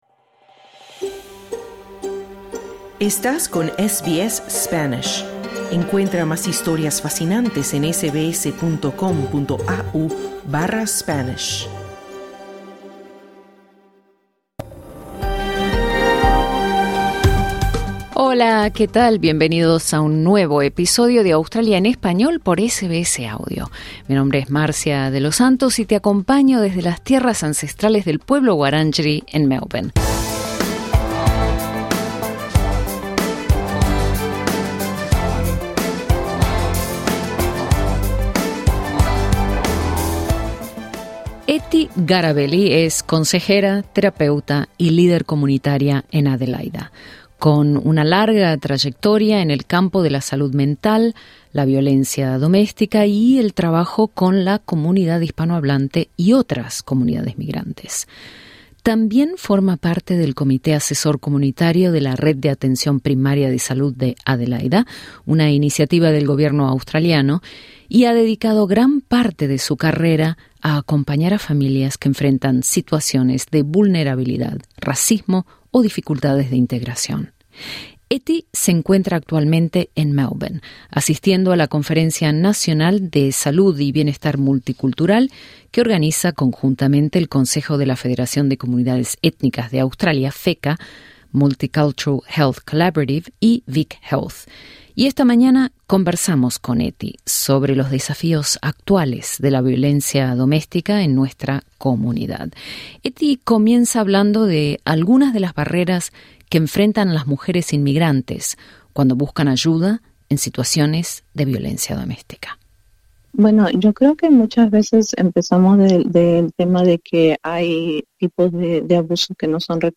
En el marco de la Conferencia Nacional de Salud y Bienestar Multicultural de FECCA en Melbourne